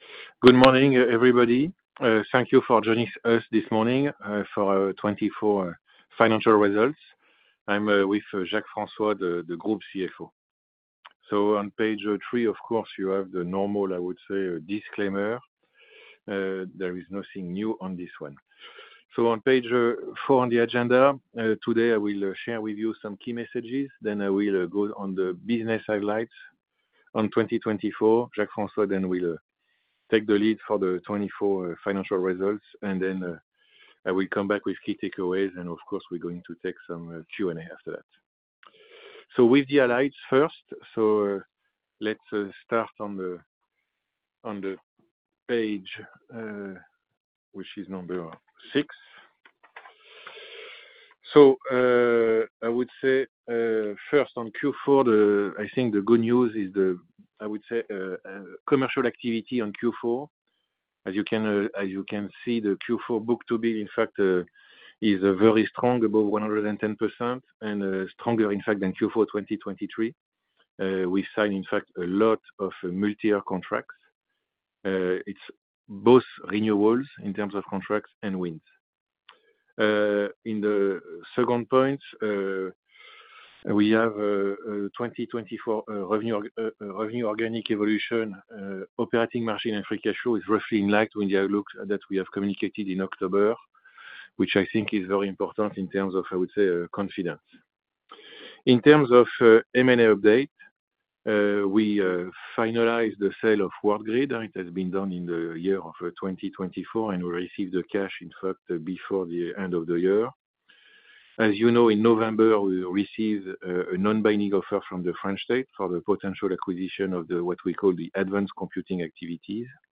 Vu l’anglais un peu brut de Philippe Salle, je ne vous garantis pas le résultat du transcripteur Python utilisé par le blog, ni de Google translate pour la partie française.